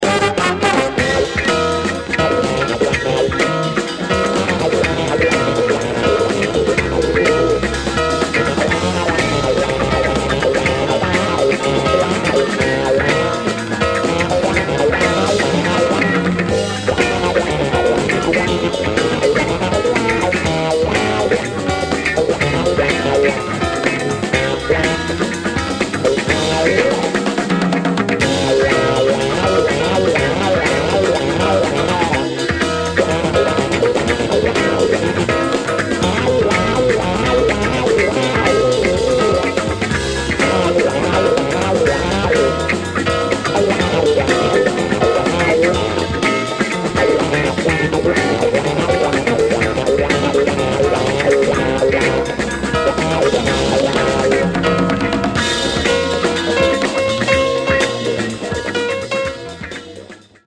considered a Norwegian jazz masterpiece from 1974.